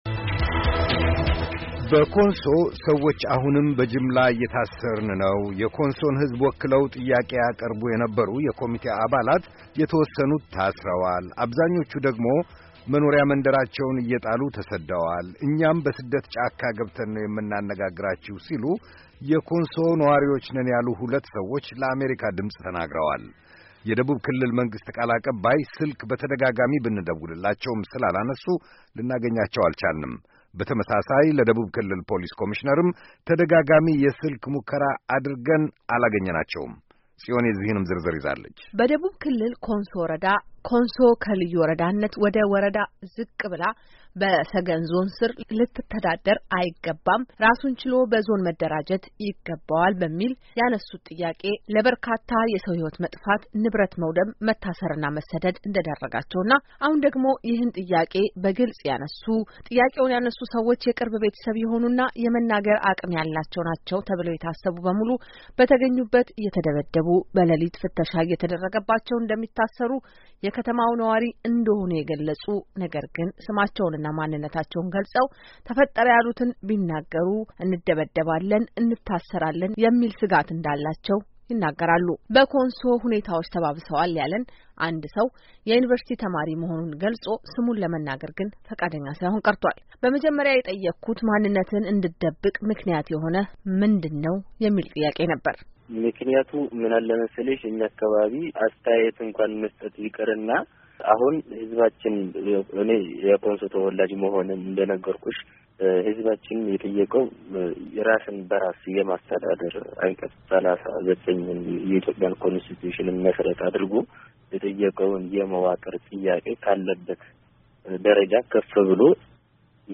በኮንሶ ሰዎች አሁንም በጅምላ እንደሚታሰሩ፣የኮንሶን ሕዝብ ወክለው ጥያቄ ያቀርቡ የነበሩ የኮሚቴ አባላት የተወሰኑት መታሰራቸውን አብዛኞቹ ደግሞ መኖሪያ መንደራቸውን ትተው መሰደዳቸውን እኛም በስደት ጫካ ገብተን ነው የምናነጋግራችሁ ሲሉ ሁለት የኮንሶ ነዋሪዎች ነን ያሉ ለአሜሪካ ድምጽ ተናገሩ።